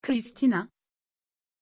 Pronunciation of Krystina
pronunciation-krystina-tr.mp3